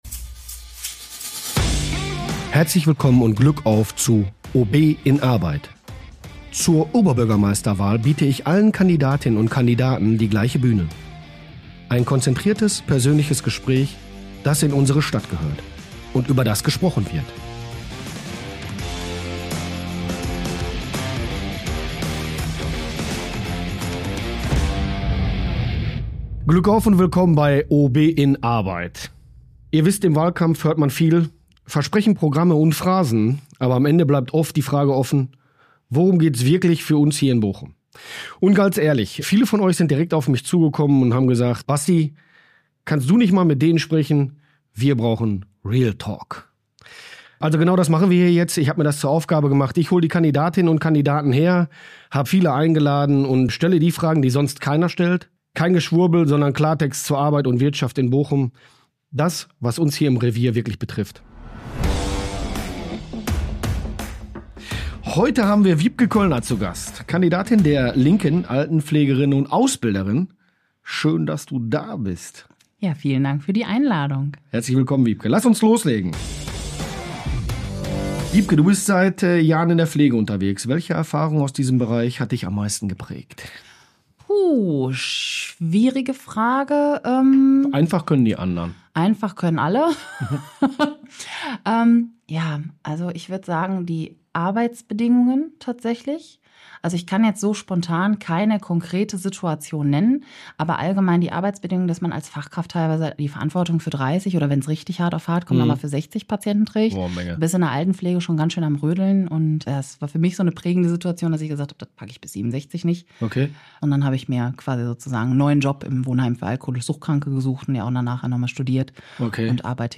Zur Oberbürgermeisterwahl biete ich allen Kandidatinnen und Kandidaten die gleiche Bühne: ein konzentriertes, persönliches Gespräch, das in unserer Stadt gehört – und über das gesprochen wird.